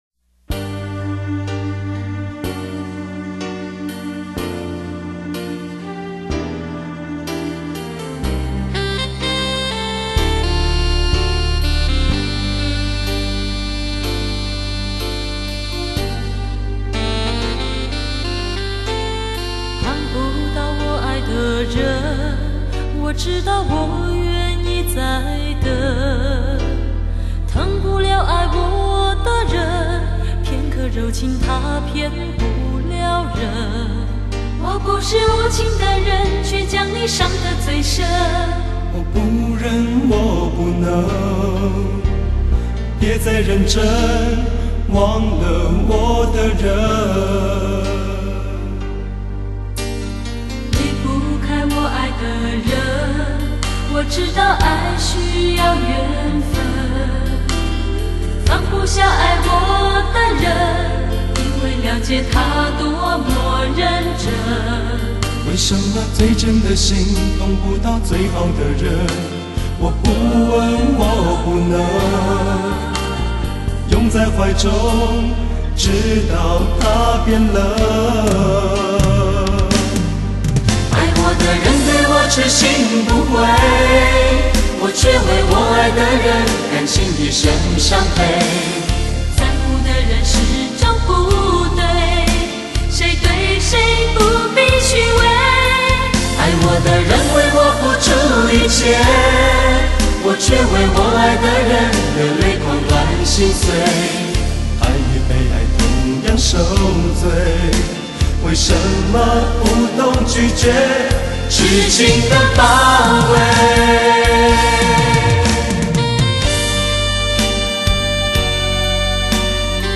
德国后期母带制作
JVC独有KZ-20Bit模拟/数码转换